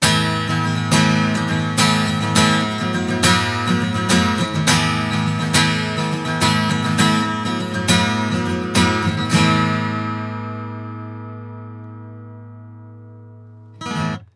実際の録り音
アコースティックギター
SM58-アコギ.wav